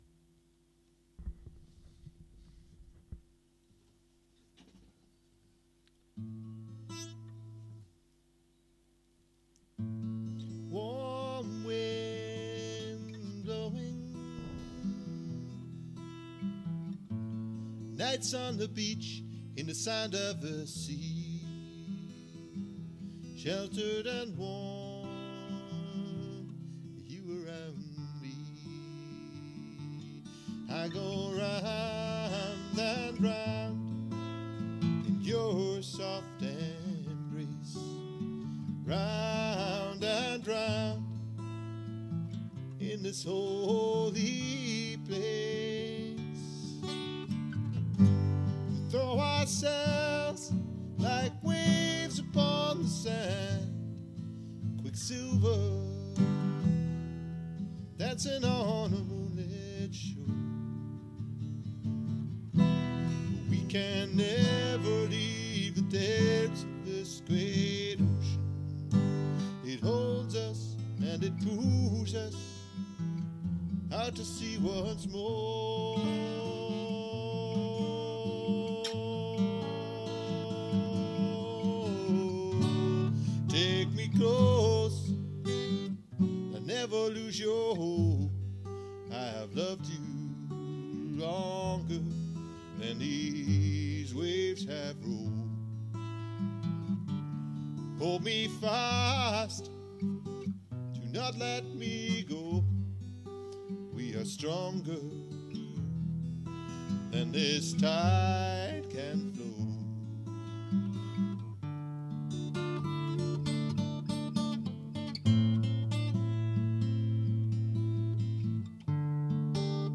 It’s a simple Am guitar song - just open Am and Em chords.
bass trombone solo